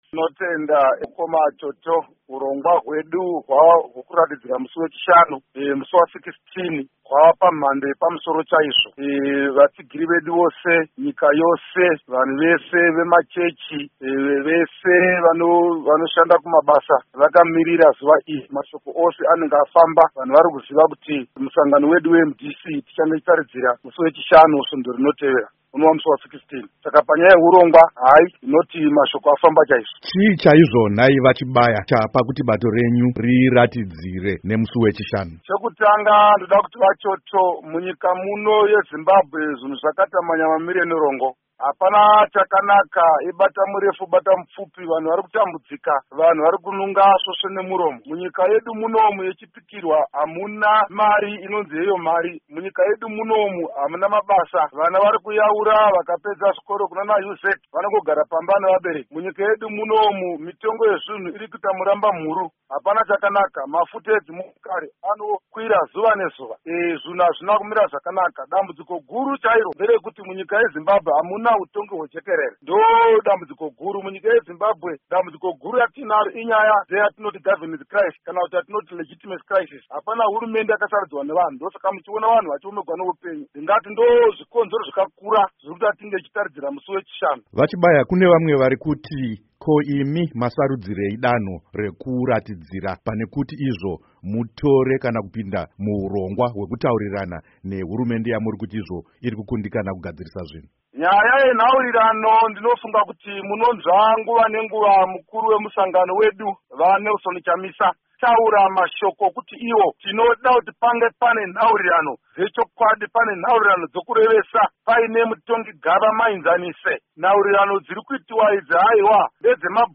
Hurukuro naVaAmos Chibaya